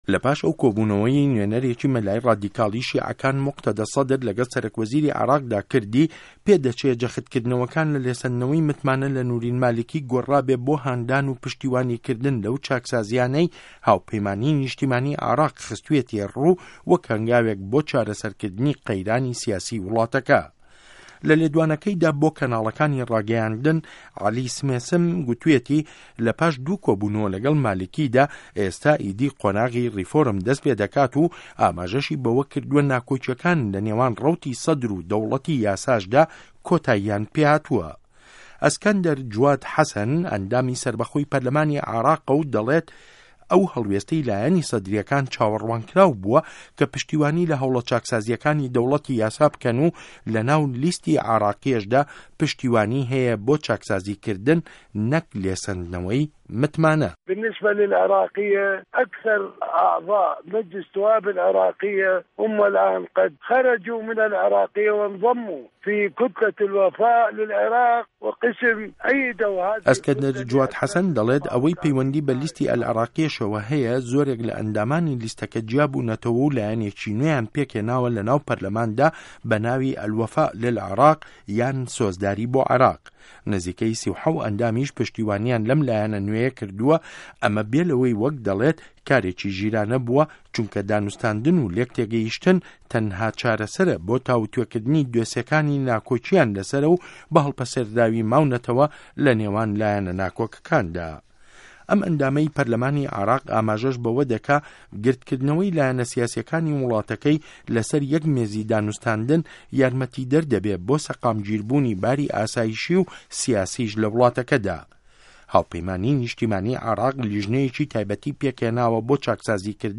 ڕاپۆرتی چاره‌سه‌ر بۆ عێراق